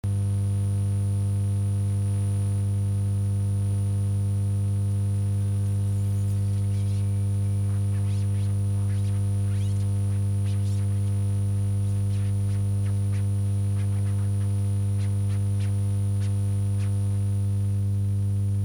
雑音埋れ何処が 雑音発生源、AC か 己 かど〜なん
noiz.mp3